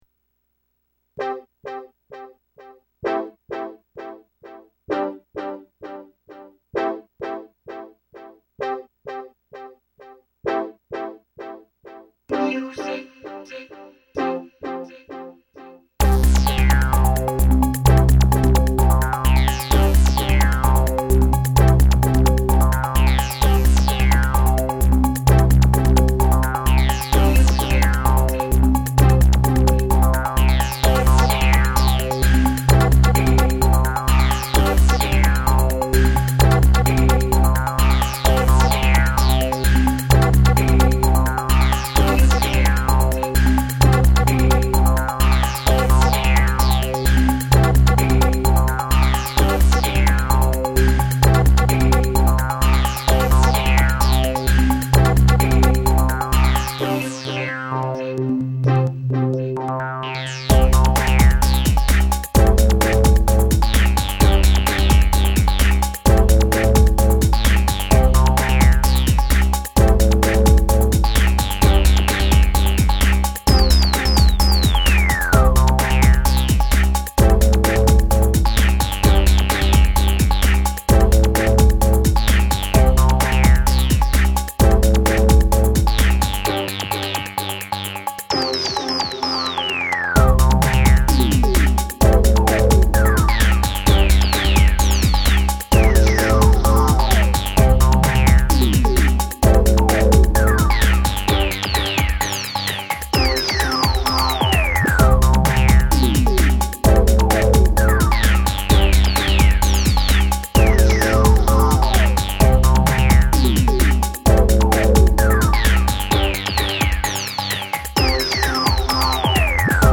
豪華協力アーティスト達によるＭＧの作成サウンドサンプル集！
俄然、ダンスの魅力は後者。